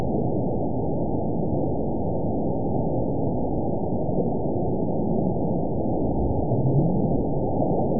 event 913791 date 04/20/22 time 19:17:54 GMT (3 years ago) score 9.41 location TSS-AB01 detected by nrw target species NRW annotations +NRW Spectrogram: Frequency (kHz) vs. Time (s) audio not available .wav